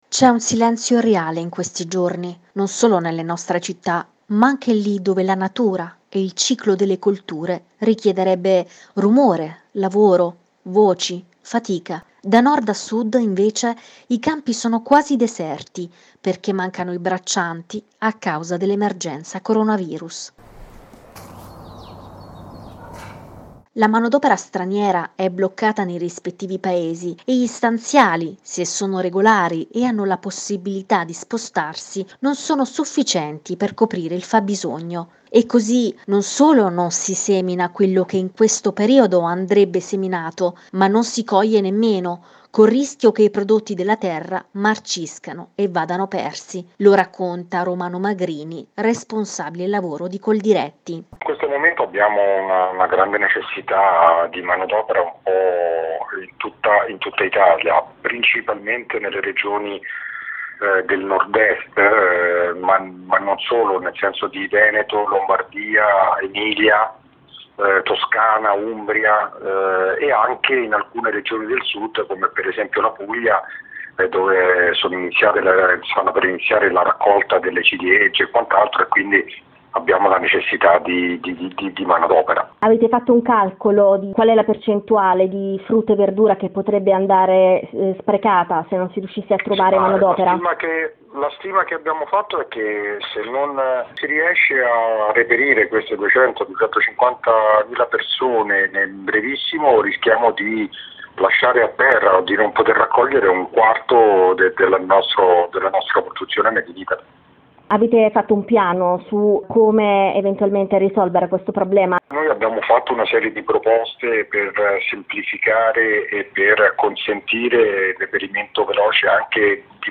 Il reportage su agricoltori e braccianti